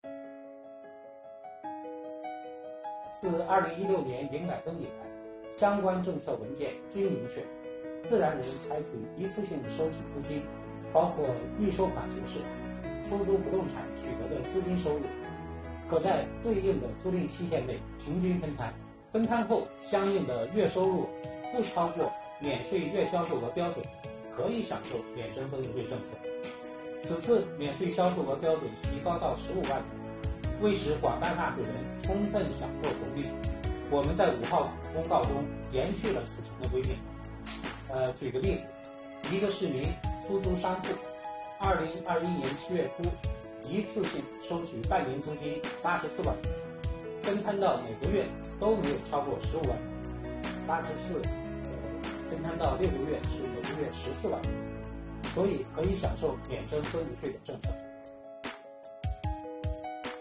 近日，国家税务总局推出最新一期“税务讲堂”课程，国家税务总局货物和劳务税司副司长吴晓强详细解读小规模纳税人免征增值税政策。